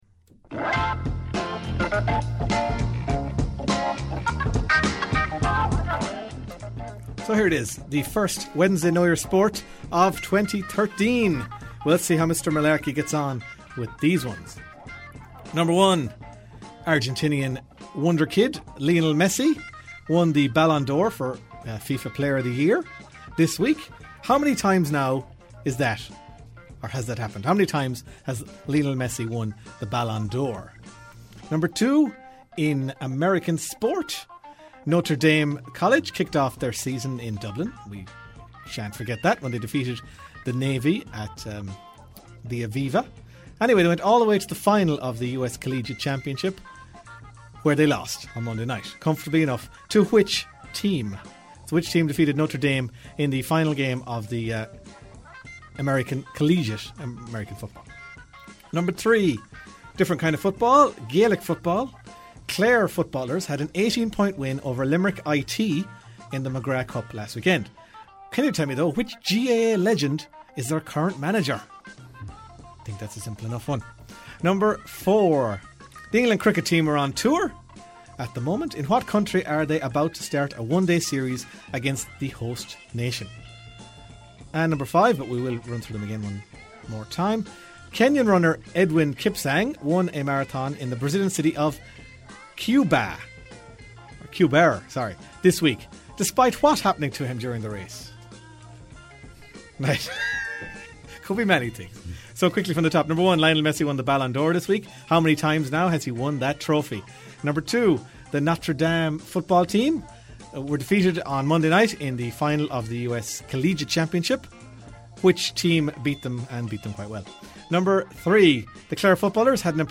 Five sports trivia questions broadcast as part of the Half-time Team Talk show on Claremorris Community Radio.